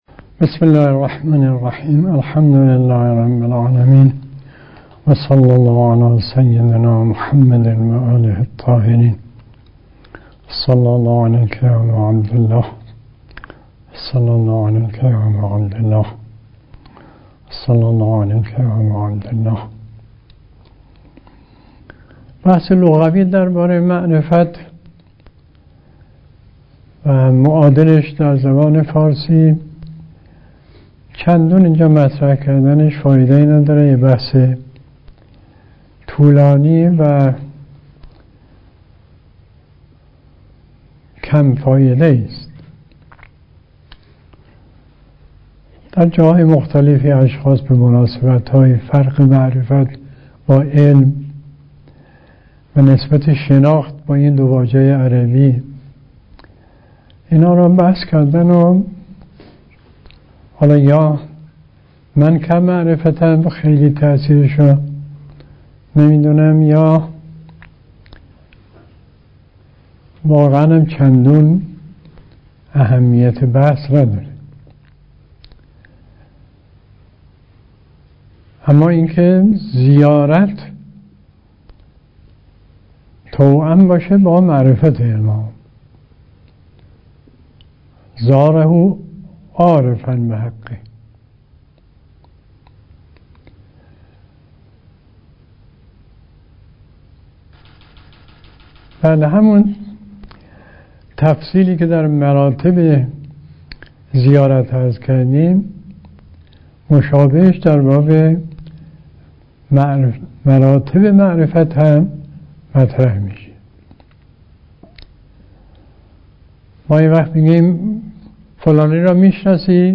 سخنرانی آیت الله مصباح یزدی